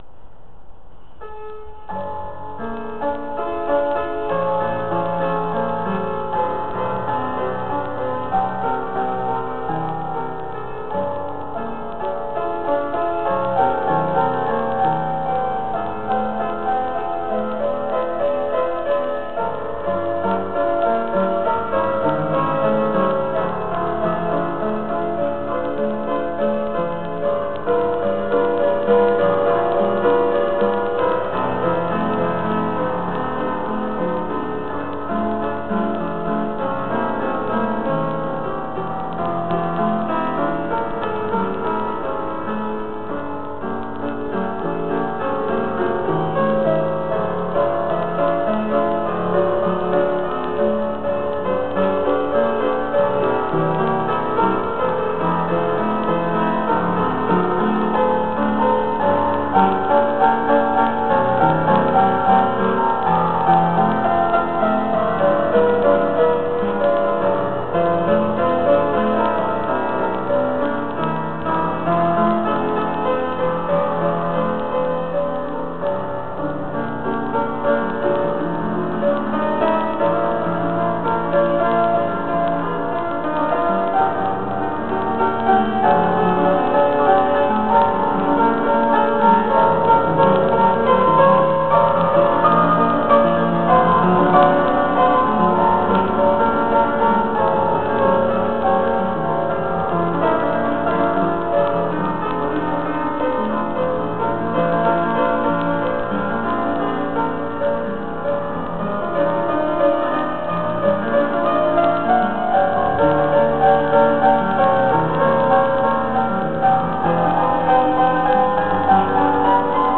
Classical dance lesson, January 6th: release improvisation.